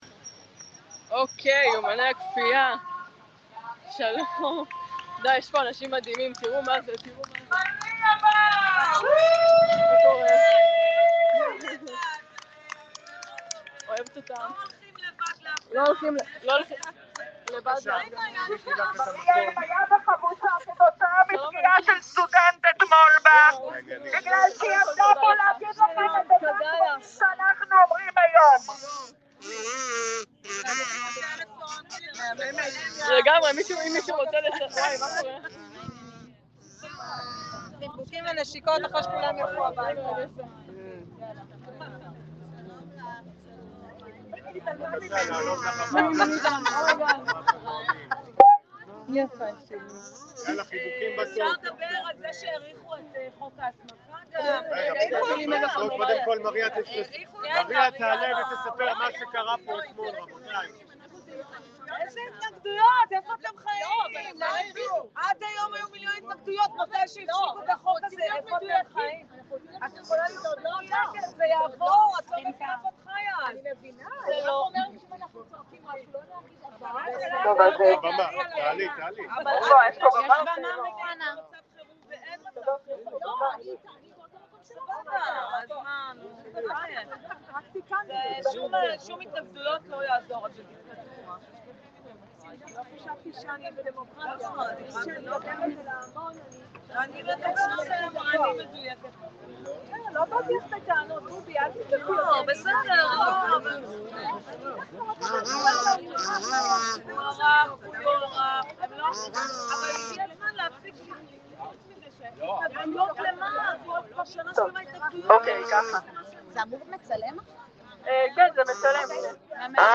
שידור חי מאוניברסיטת תל אביב